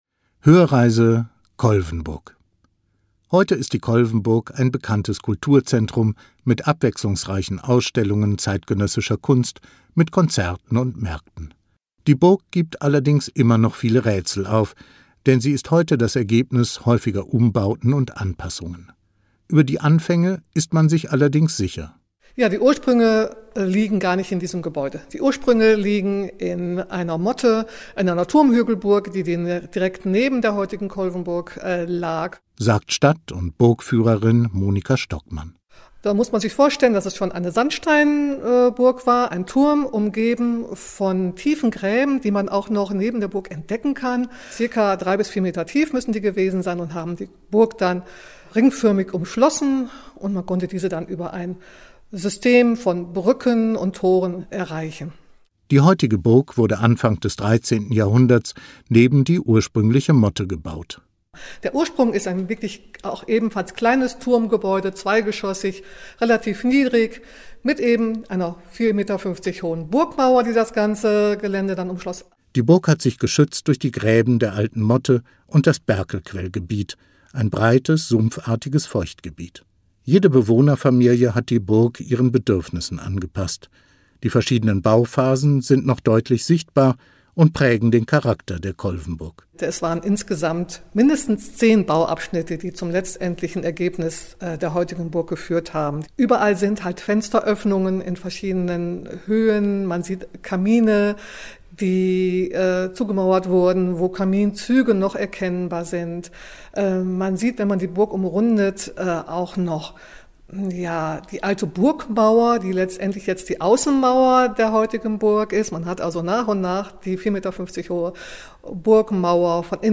akustische Privatführung.